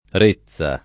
rezza [ r %ZZ a ] s. f.